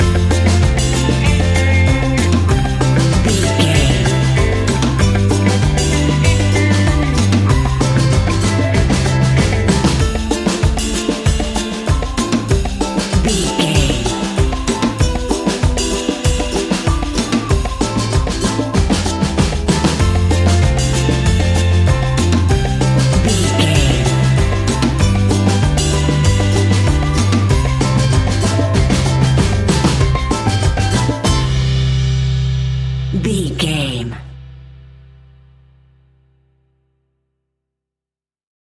Uplifting
Ionian/Major
steelpan
worldbeat
drums
percussion
bass
brass
guitar